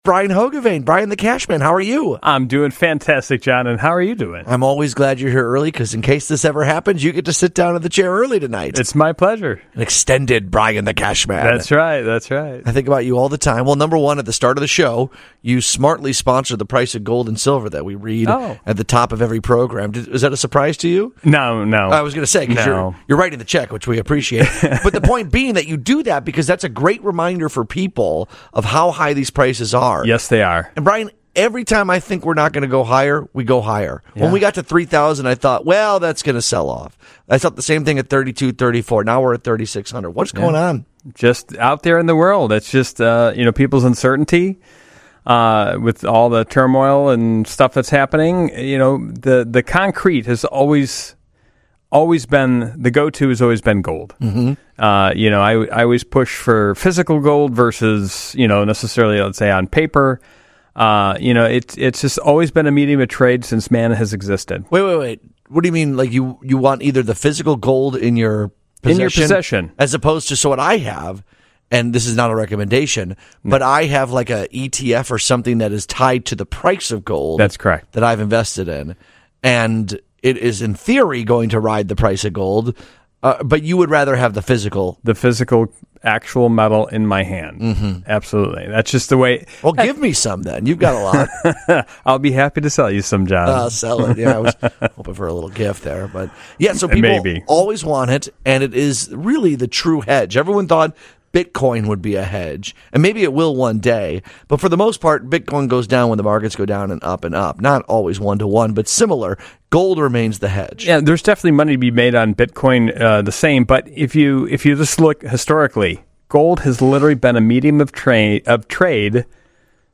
Listeners call in to ask about posters of The Beatles, stadium seats, and signed prints.